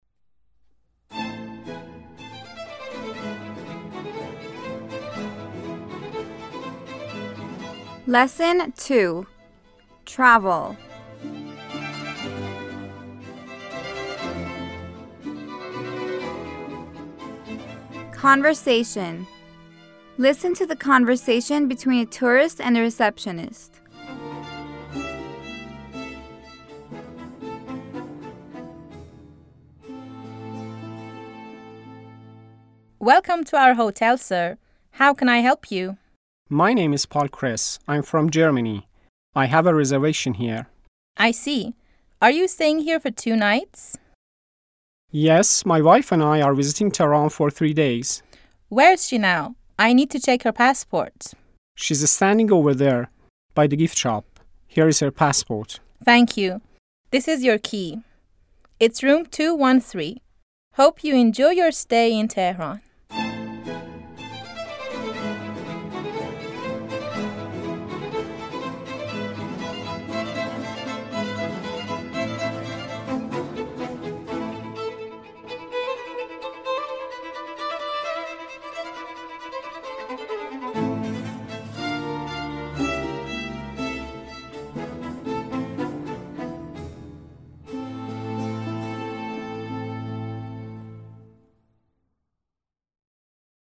9-L2-Conversation
9-L2-Conversation.mp3